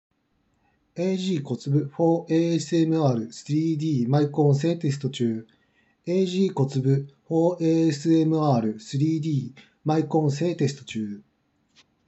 マイク性能は少し雑味がある
✅「ag COTSUBU for ASMR3D 」のマイク性能